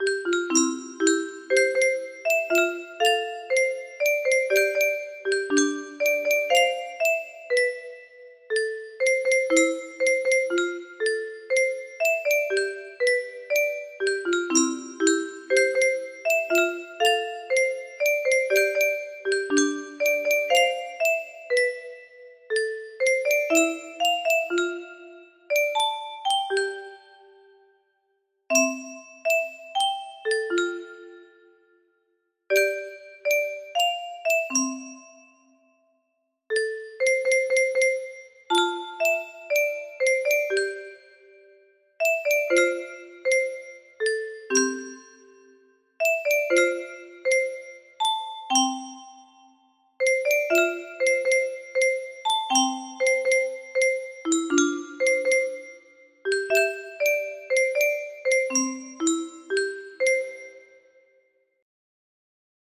BPM 120